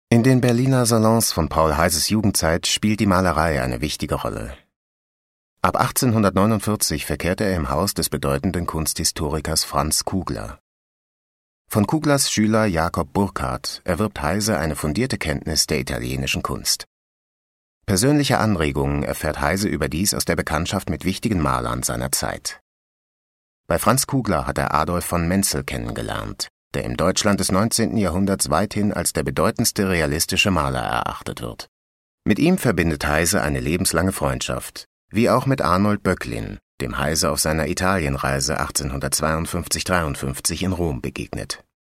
Audioguide: Paul Heyse